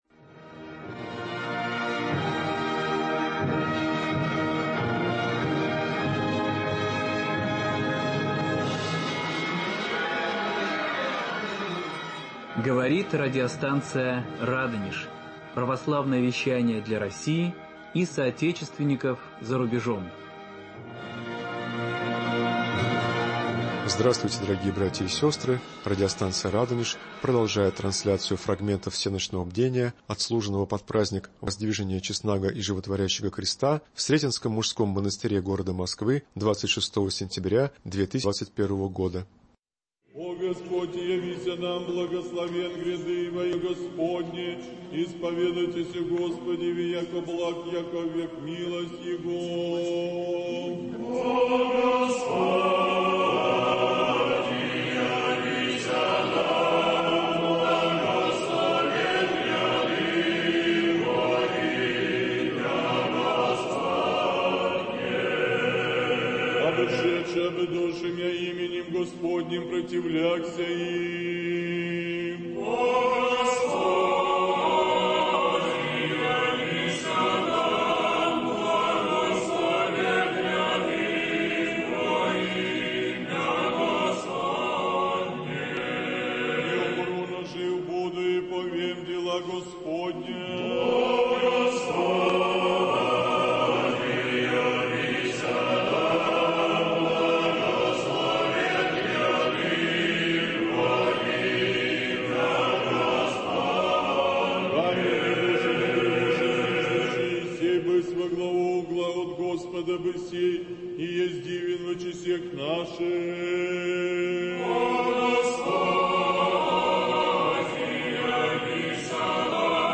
Фрагменты всенощного бдения под праздник Воздвижения Креста Господня, отслуженного в Московском Сретенском мужском монастыре 26 сентября 2021 года.